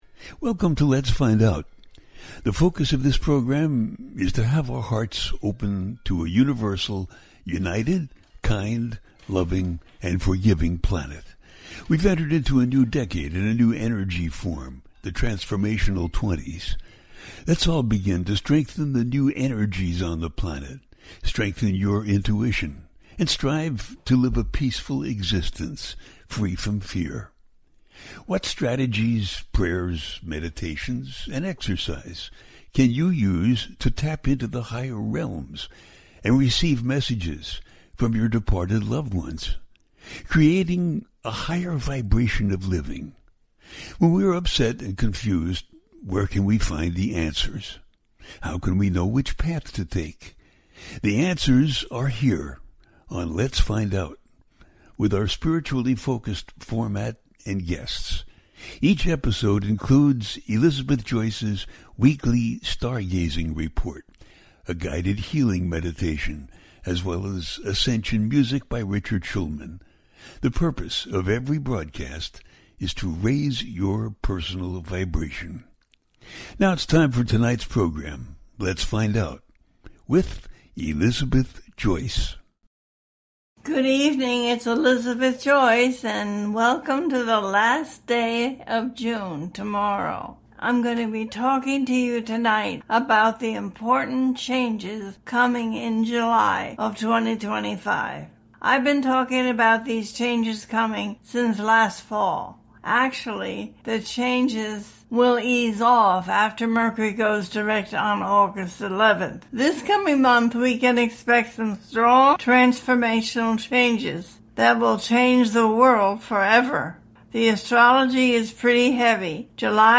The Important Changes In July 2025 - A teaching show
The listener can call in to ask a question on the air.
Each show ends with a guided meditation.